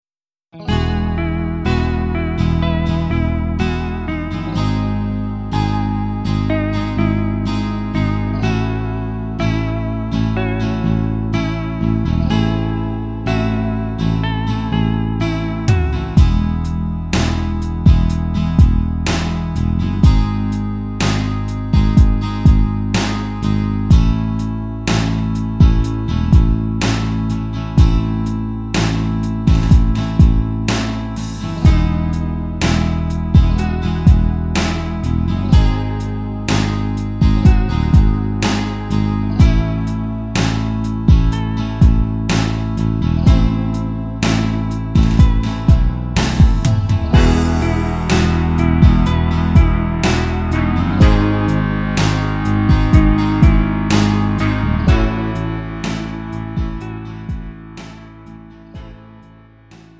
음정 -1키 3:47
장르 가요 구분 Lite MR
Lite MR은 저렴한 가격에 간단한 연습이나 취미용으로 활용할 수 있는 가벼운 반주입니다.